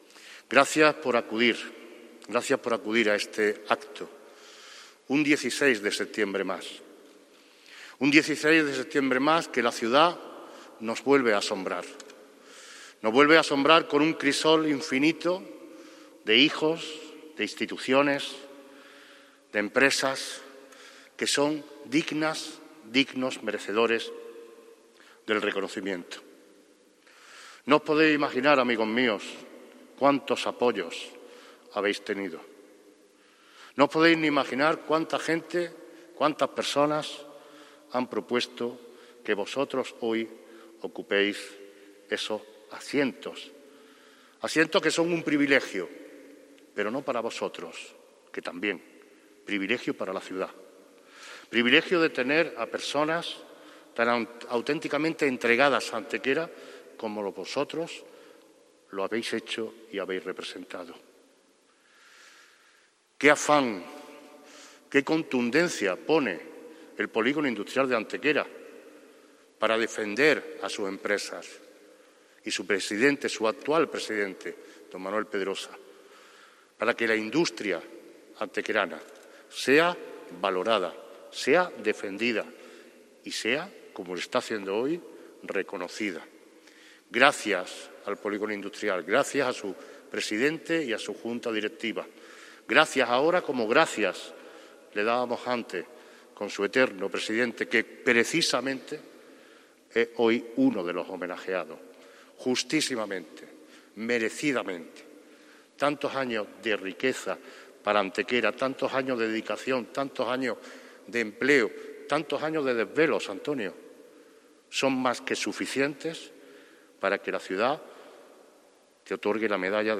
La Real Colegiata de Santa María la Mayor ha acogido en el mediodía de hoy jueves 16 de septiembre el acto institucional de entrega de distinciones de honor del Ayuntamiento de Antequera, iniciativa que se desarrolla coincidiendo con el mismo día en que se cumplen 611 años de la conquista de Antequera por parte del Infante Don Fernando y la proclamación de Santa Eufemia como Patrona de la ciudad.
Cortes de voz